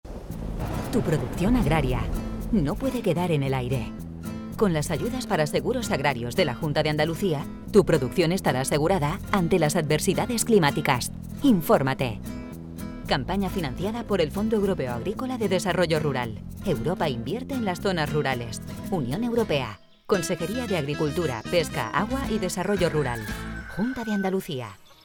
AGRICULTURA_SEGUROS_CUÑA.mp3